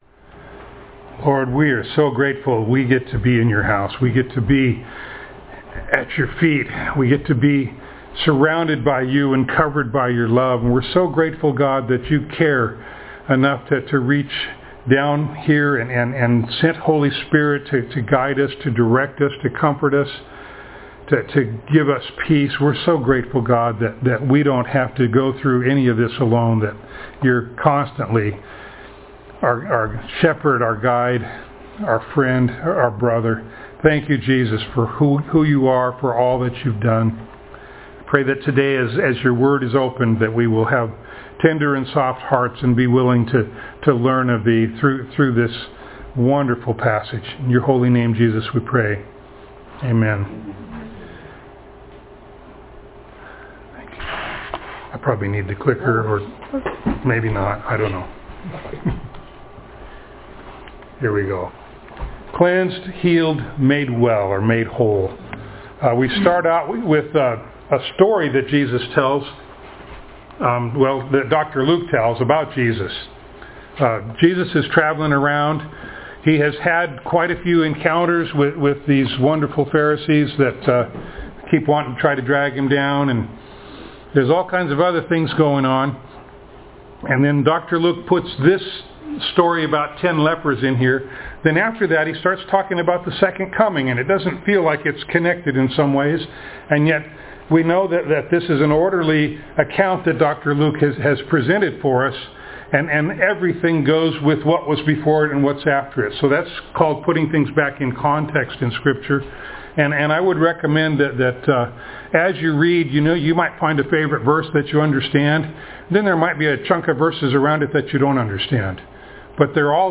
Passage: Luke 17:11-37 Service Type: Sunday Morning